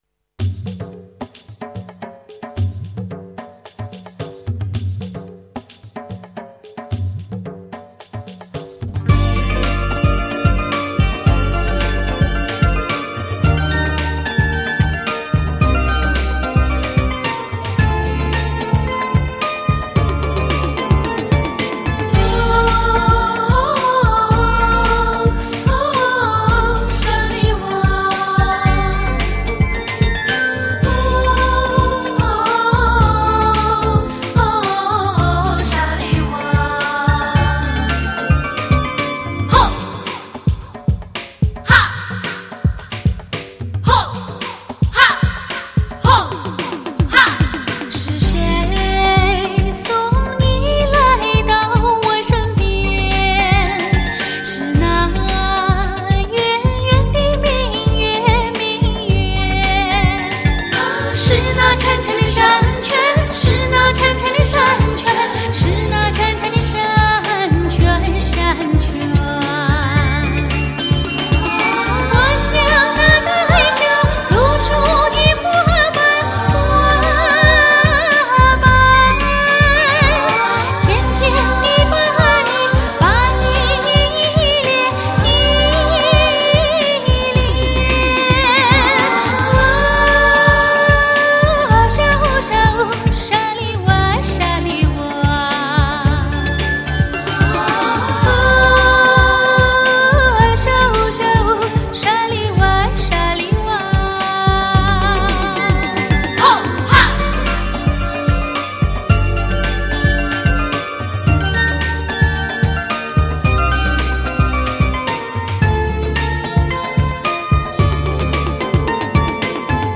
美妙纯净的歌声带你畅快的遨游在“天与人的幻境”